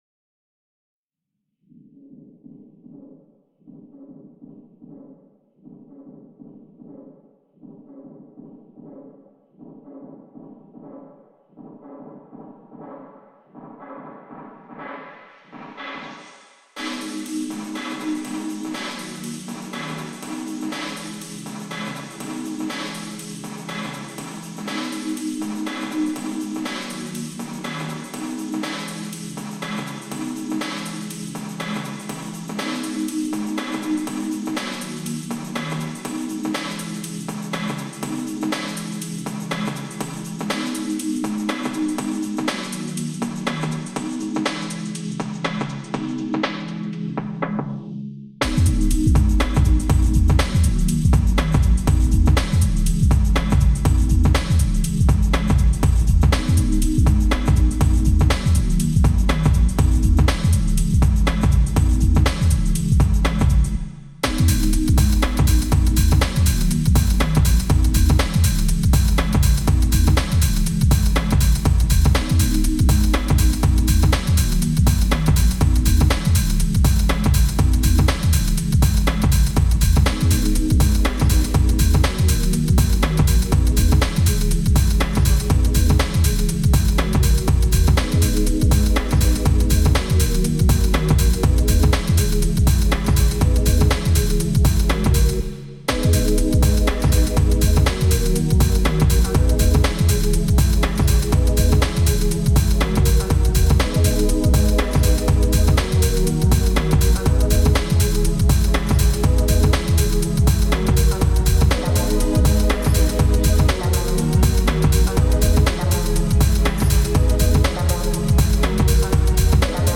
Semplicemente Techno.